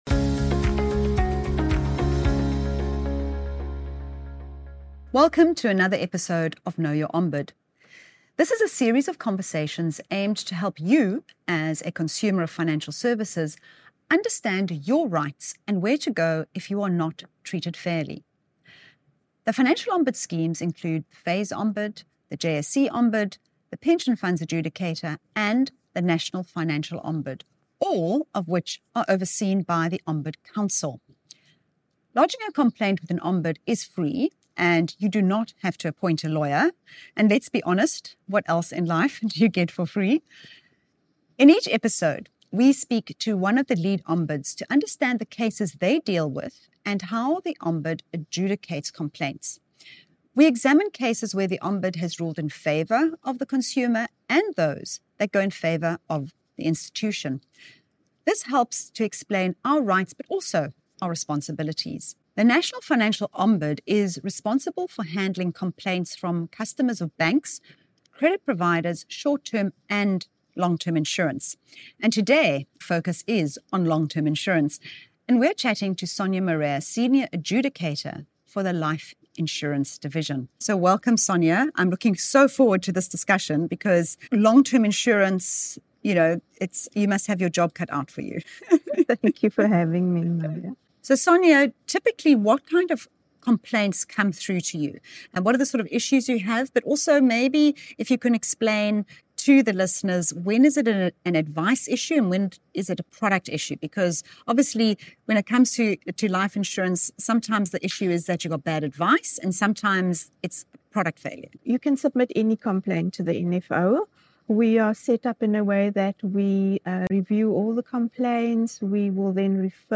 The conversation also addresses the often-overlooked nuances of funeral policies and why you cannot take out funeral cover on someone who is not directly related to you.